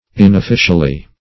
Search Result for " inofficially" : The Collaborative International Dictionary of English v.0.48: Inofficially \In`of*fi"cial*ly\, adv.